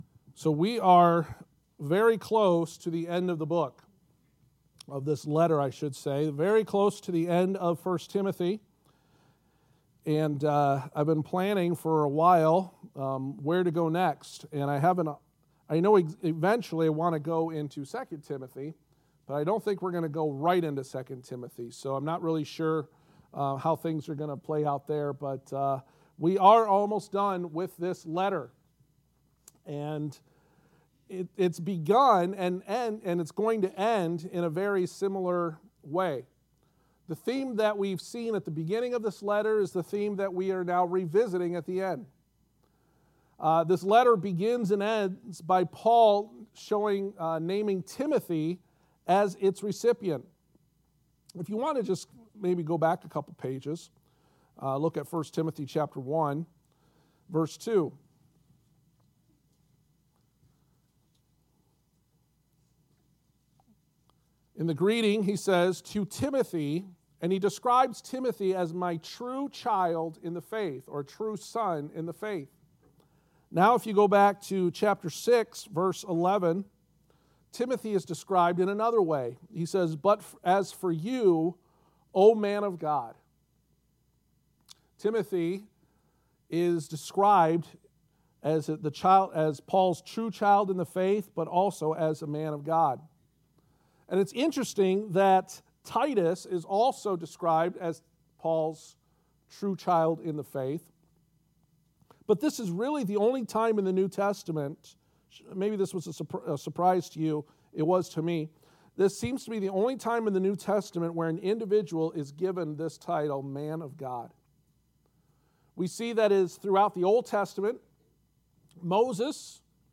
The Pastoral Epistles Passage: 1 Timothy 6:11-16 Service Type: Sunday Morning « Godliness-Our Highest Gain The Man or Woman of God